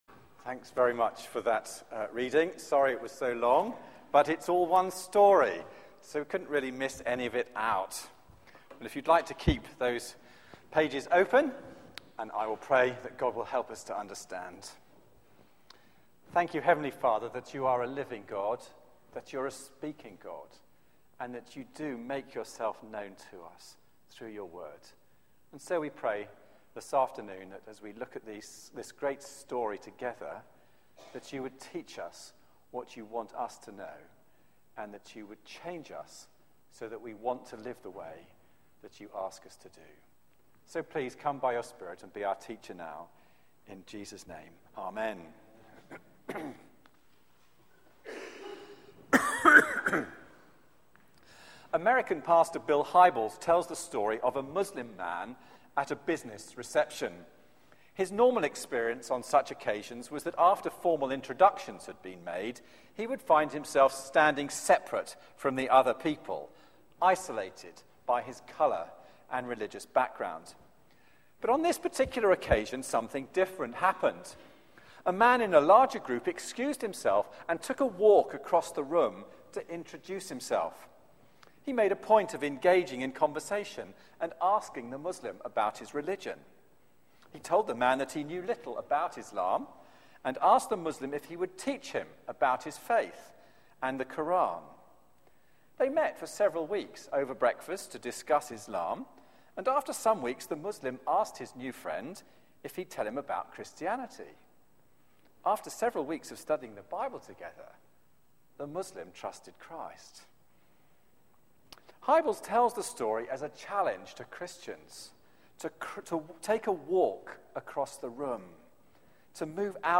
Media for 4pm Service on Sun 03rd Feb 2013 16:00 Speaker
Theme: Crossing the divide Sermon